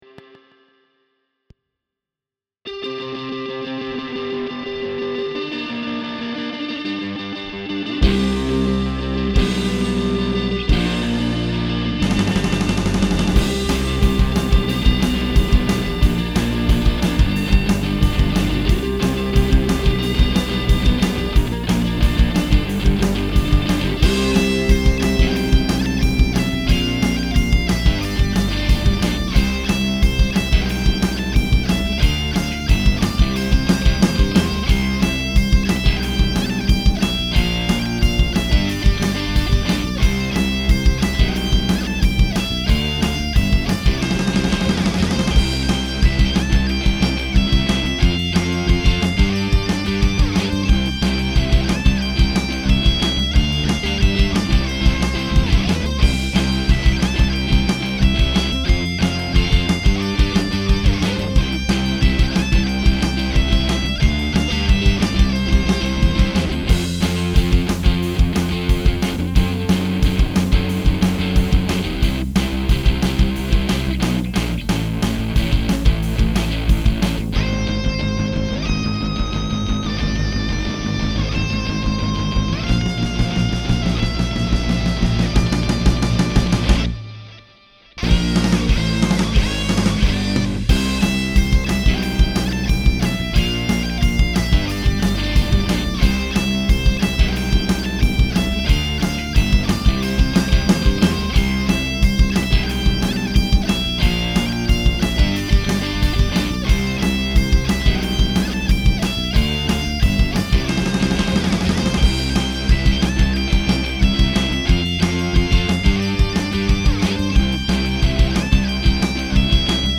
Clica aquí Original Un nou amic - Original Instrumental Santi un nou amic - Instrumental Estil Rock Santi un nou amic - Rock Estil vals Santi un nou amic - Vals CANÇÓ CONTE “ COM EM VEUS?
Santi-un-nou-amic-rock.mp3